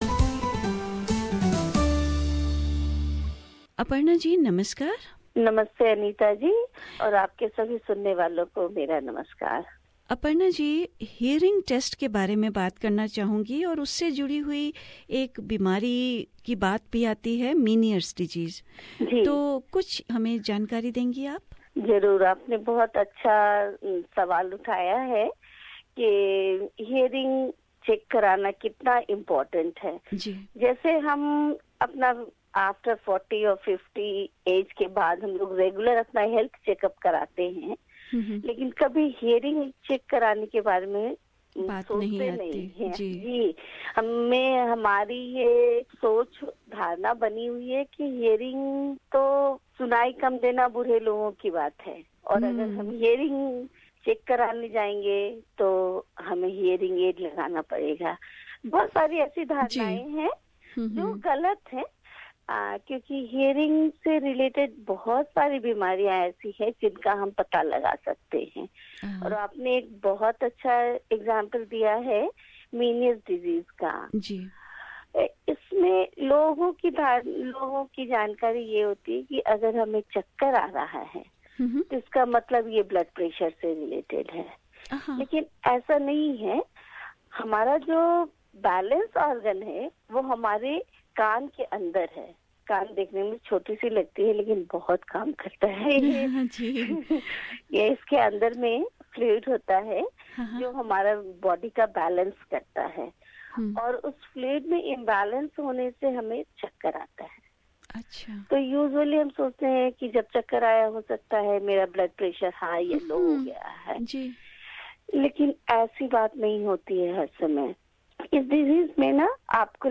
Tune in to the talk with audiologist to understand the hearing problems, disease and the importance of hearing test.